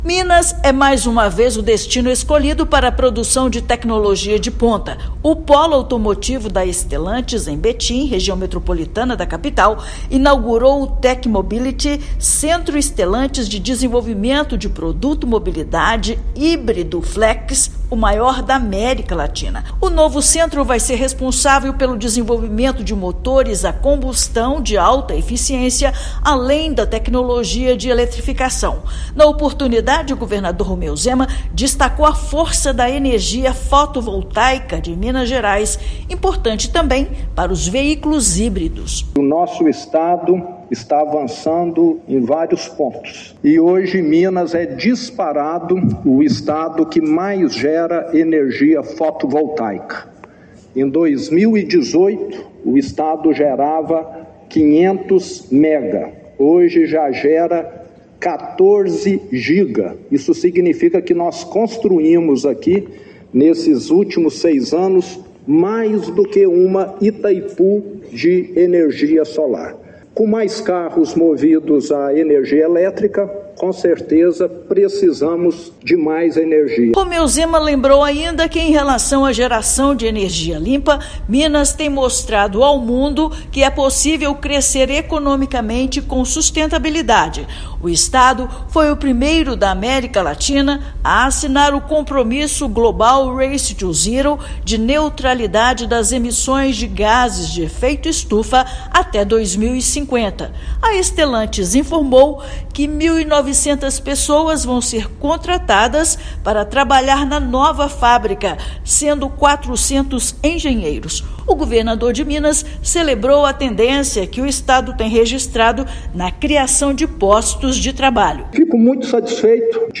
[RÁDIO] Governador destaca parceria com quem investe em Minas Gerais na inauguração da nova produção de motores da Stellantis
Quase 2 mil trabalhadores serão contratados para o desenvolvimento de propulsores a combustão de alta eficiência, além de tecnologias de eletrificação de baixa e alta voltagem. Ouça matéria de rádio.